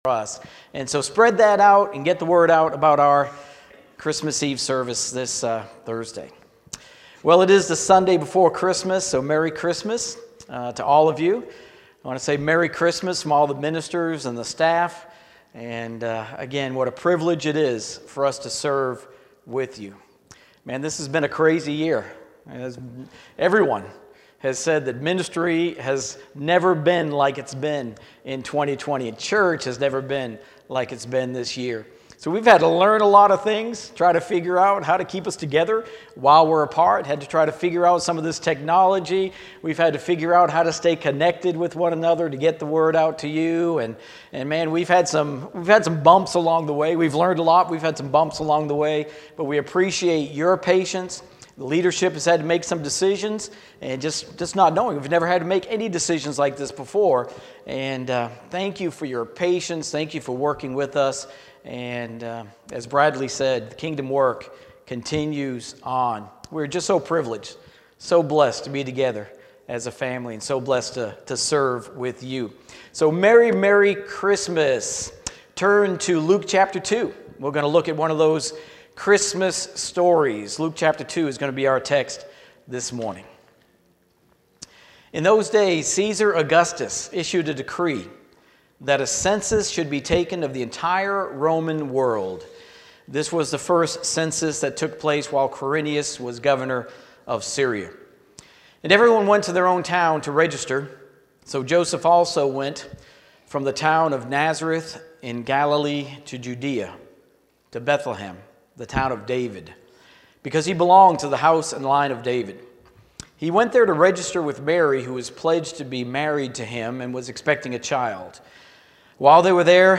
Sermons | Park Avenue Church of Christ